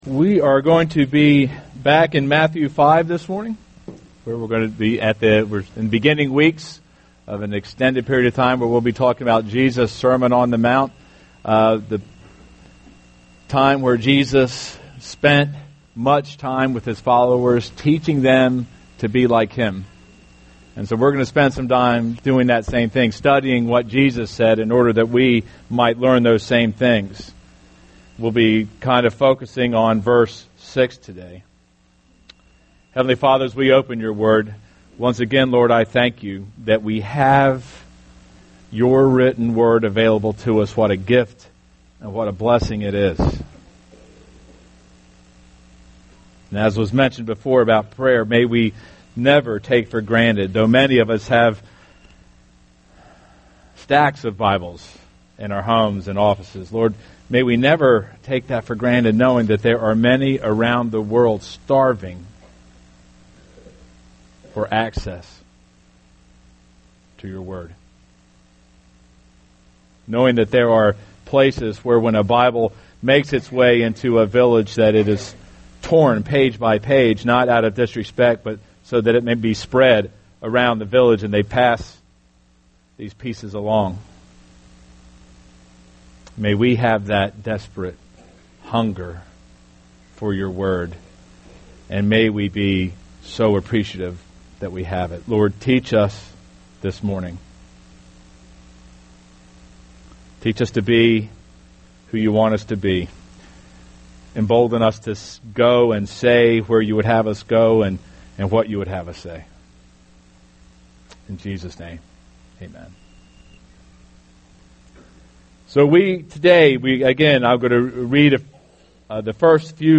Sermon on the Mount – Hunger and Thirst for Righteousness